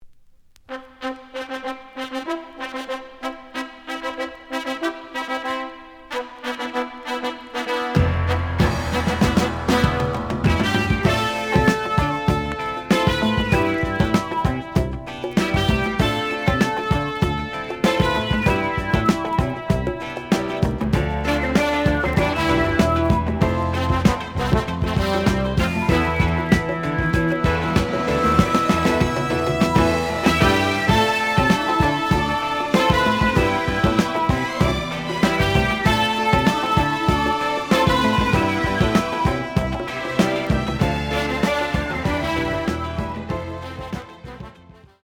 The audio sample is recorded from the actual item.
●Format: 7 inch
●Genre: Funk, 70's Funk